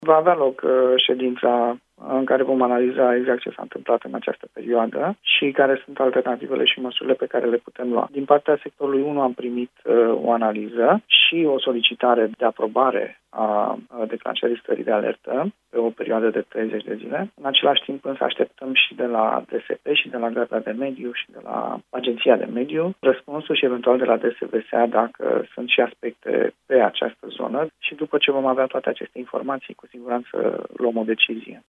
Deși Romprest a reluat activitatea de ridicare a gunoiului în sectorul 1, prefectul Capitalei, Alin Stoica a spus la Europa Fm că e nevoie de o analiză a cât de gravă este situația și de un răspuns la solicitarea primarului Clotilde Armand de declarare a stării de alertă pentru 30 de zile.